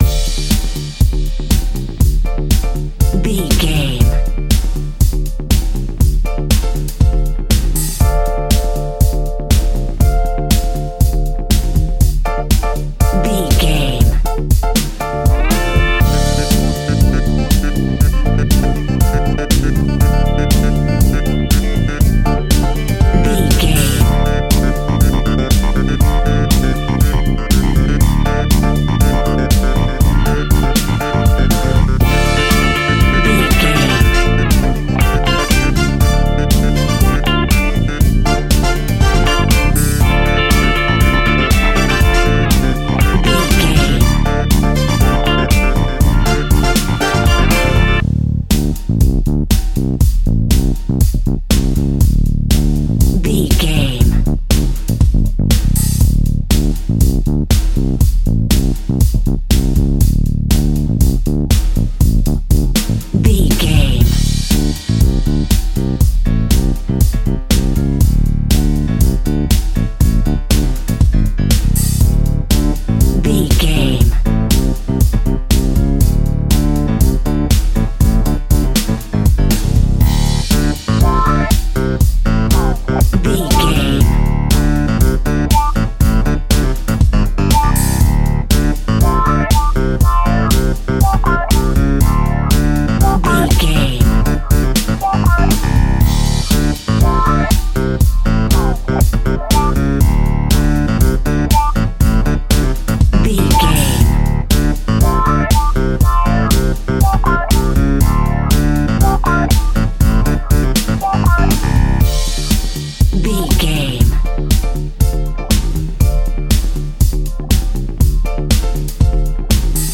Aeolian/Minor
D
funky
groovy
uplifting
driving
energetic
strings
brass
bass guitar
electric guitar
electric organ
synthesiser
drums
funky house
disco house
electro funk
upbeat
synth leads
Synth Pads
synth bass
drum machines